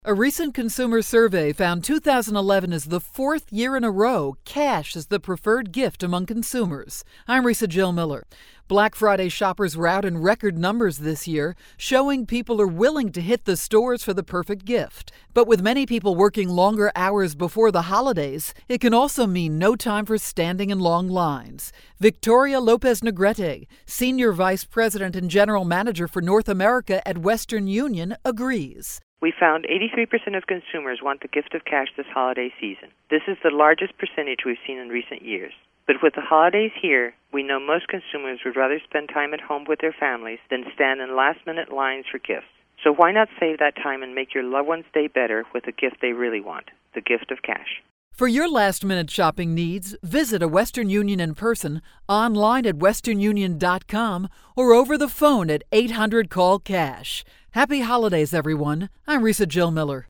December 15, 2011Posted in: Audio News Release